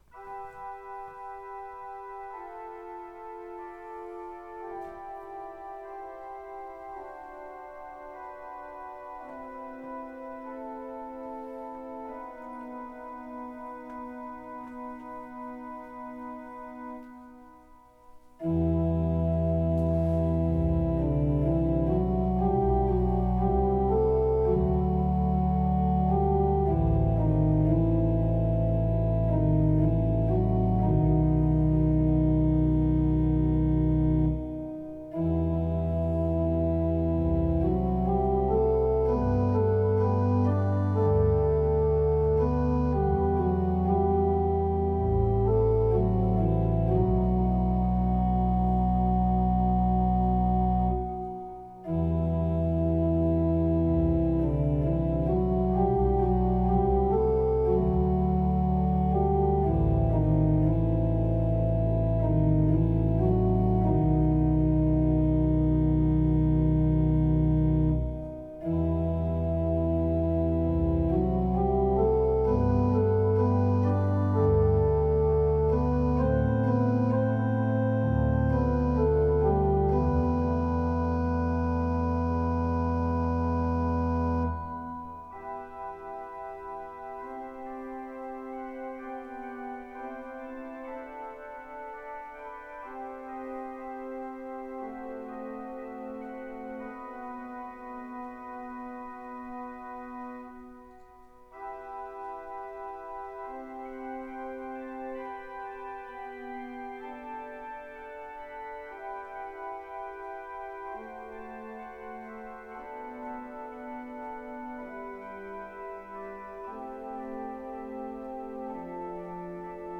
The 4’ pedal section in the middle of the piece represents her passing.
The triumphal chords at the end of the section represent the resurrection. This section tapers out at the end as a moment of quiet contemplation after the victory is won.